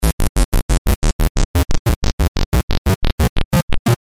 描述：嗡嗡声，FX，电子，Zap，2Beat
标签： 120 bpm Electronic Loops Bass Loops 689.19 KB wav Key : Unknown
声道立体声